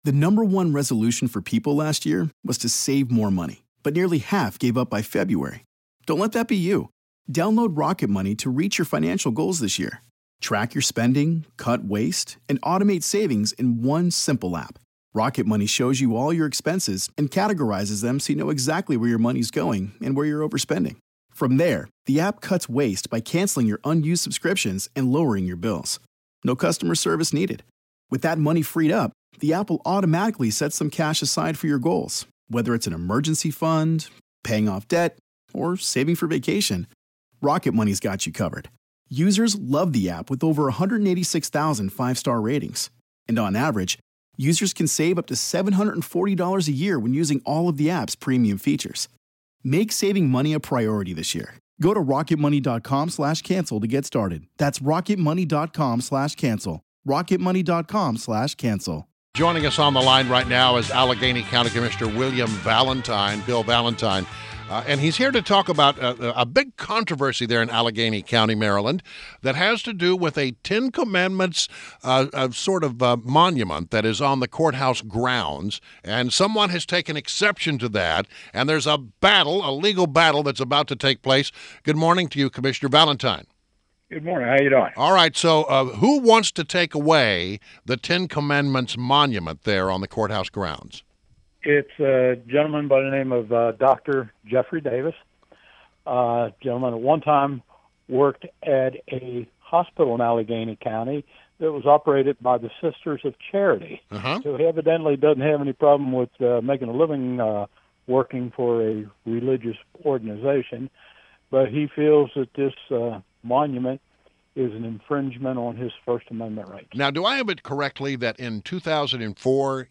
INTERVIEW -- Allegany County Commissioner WILLIAM "BILL" VALENTINE